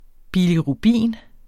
Udtale [ biliʁuˈbiˀn ]